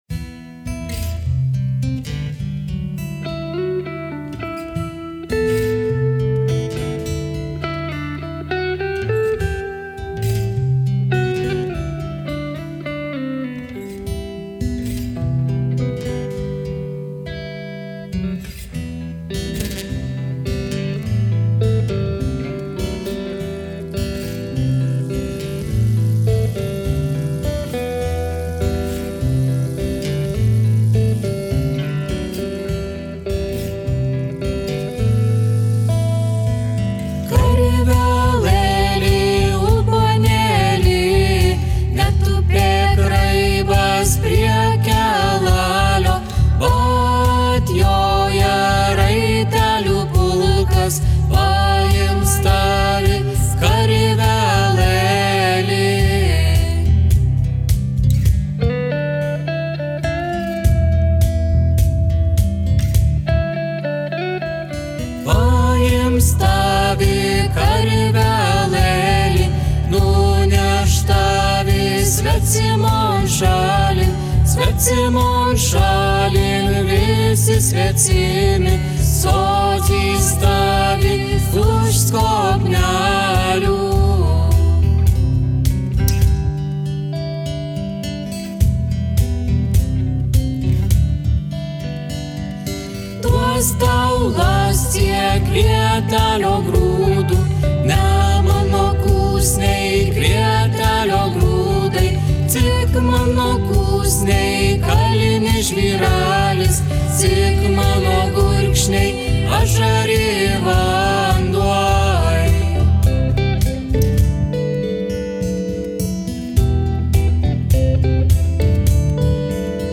akustinė ir elektrinė gitara, pianinas, perkusija
bosinė gitara